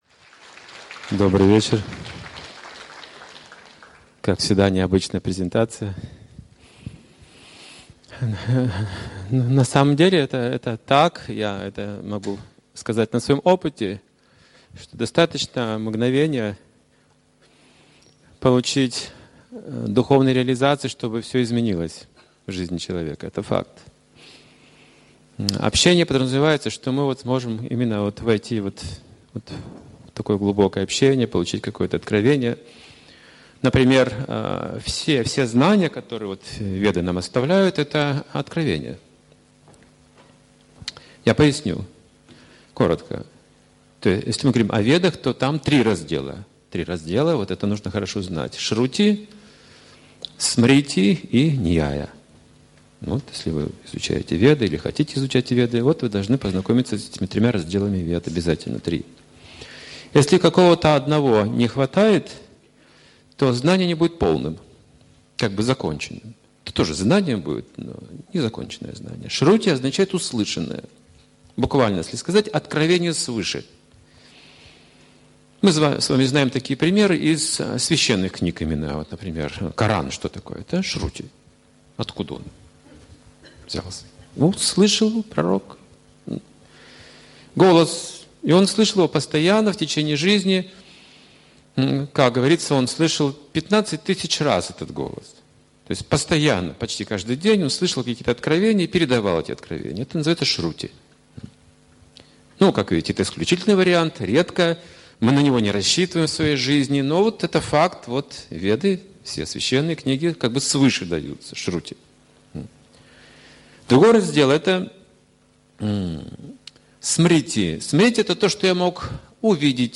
Темы, затронутые в лекции: Особенности ведических знаний Пять измерений, в которых мы живем Энергия пищи Зачем нужны еда, сон, совокупление и оборона?